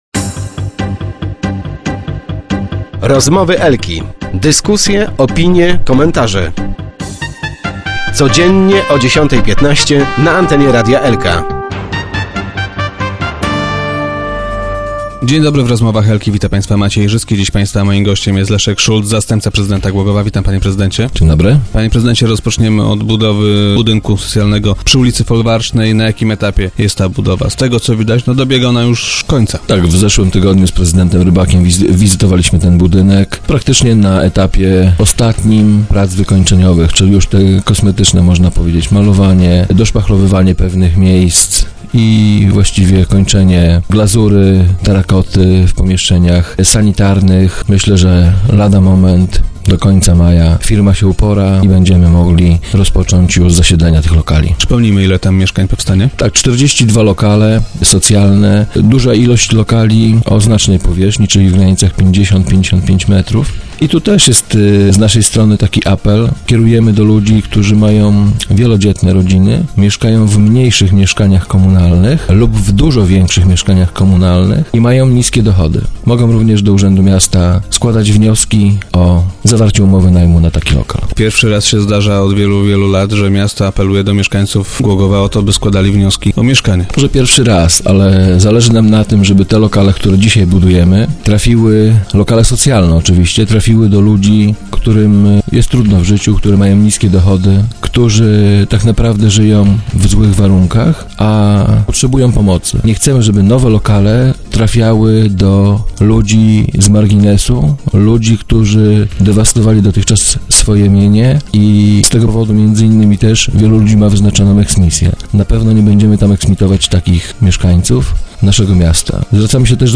W budynku powstaną 42 mieszkania. - Chcemy, żeby dostali je głogowianie, którzy ich na prawdę potrzebują - powiedział wiceprezydent Leszek Szulc, który był dziś gościem Rozmów Elki.